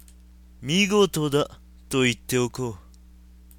RPG戦闘終了後キャラクター台詞です。
③、④あたりはA、B然して声が違わないような･･･。
しっかし、マイクの集音力高すぎです。
マウスのクリック音まで入ってますよ。